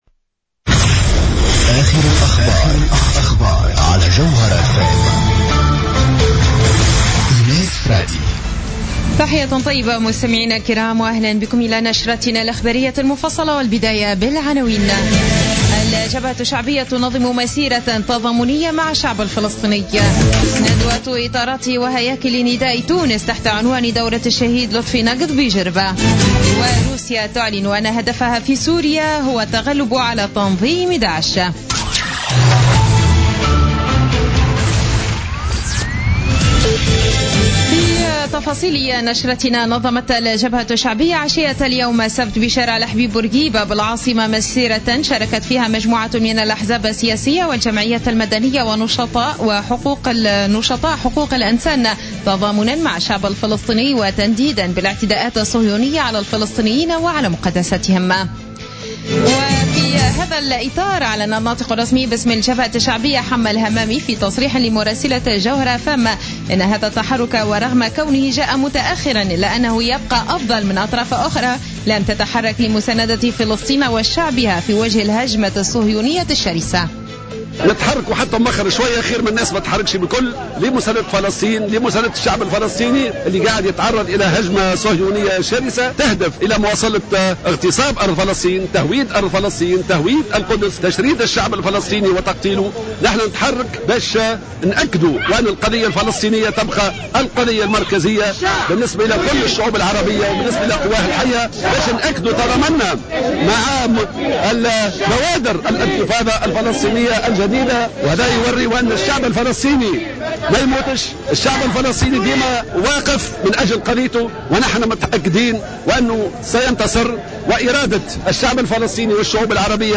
نشرة أخبار الساعة 19 ليوم السبت 17 أكتوبر 2015